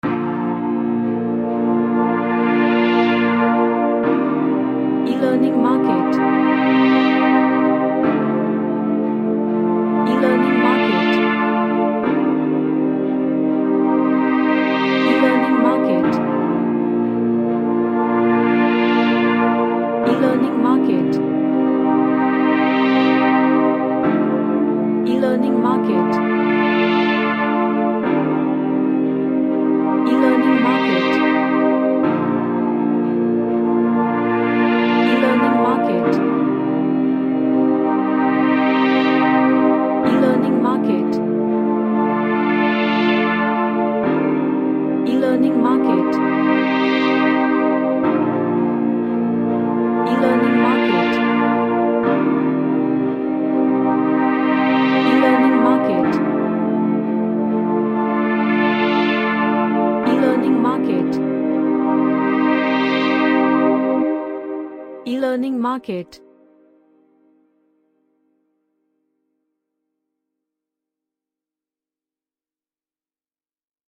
A Relaxing ambient track with slowed pads.
Relaxation / Meditation